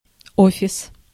Ääntäminen
Synonyymit контора кабинет бюро канцелярия присутствие Ääntäminen Tuntematon aksentti: IPA: /ˈofʲɪs/ Haettu sana löytyi näillä lähdekielillä: venäjä Käännöksiä ei löytynyt valitulle kohdekielelle.